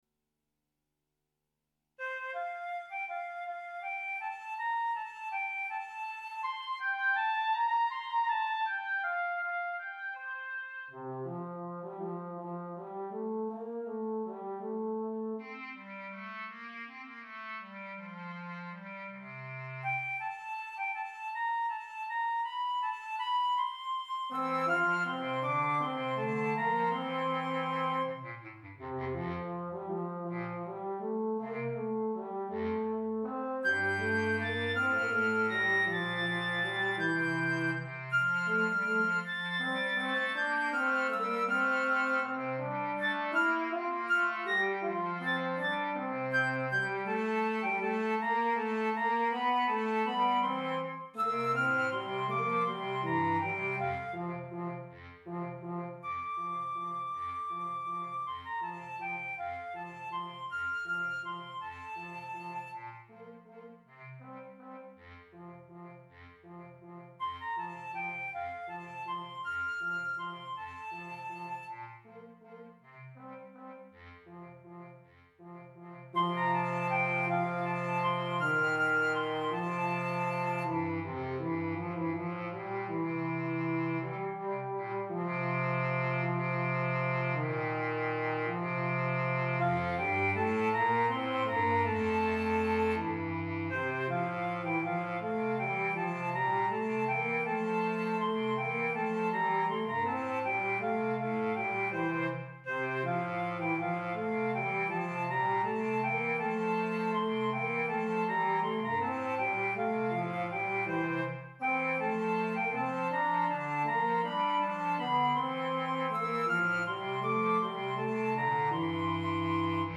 minus Instrument 2